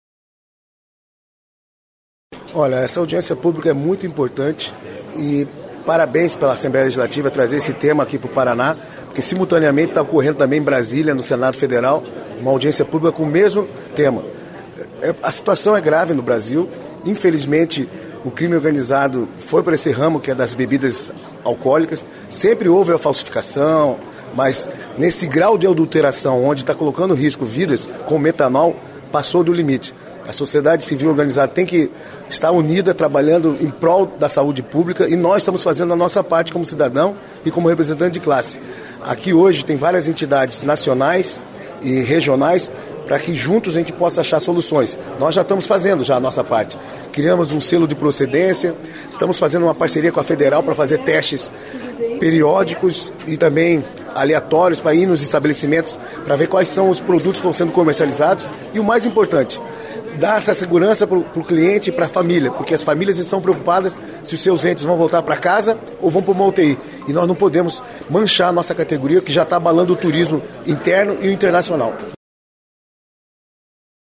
A Assembleia Legislativa do Paraná (ALEP) sediou, nesta quarta-feira (15), uma audiência pública para discutir os riscos do metanol à saúde e estratégias de combate à adulteração de bebidas alcoólicas. O encontro ocorreu no Plenarinho e reuniu autoridades do estado, representantes do setor produtivo e especialistas.